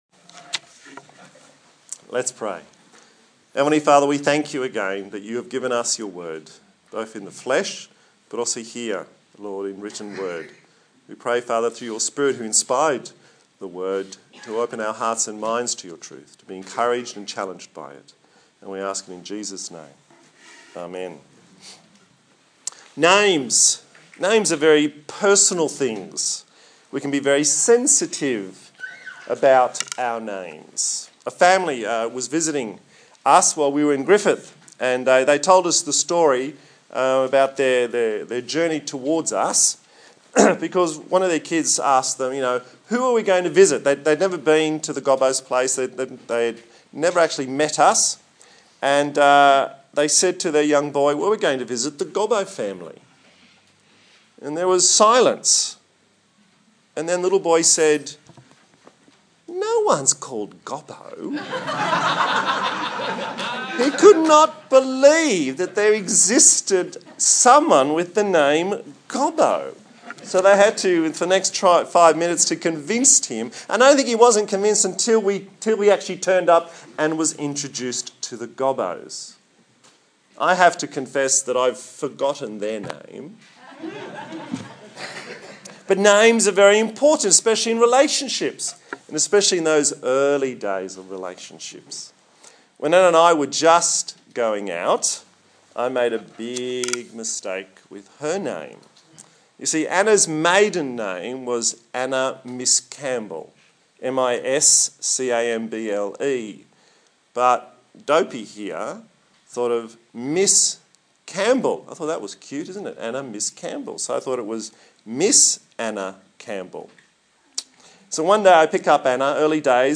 The Trinity Passage: Hebrews 4:14-5:10 Service Type: Sunday Morning